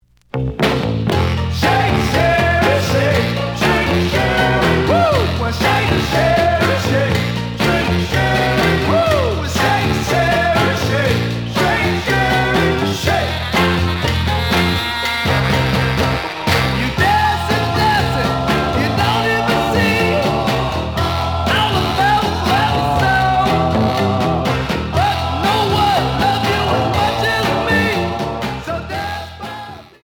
試聴は実際のレコードから録音しています。
●Genre: Soul, 60's Soul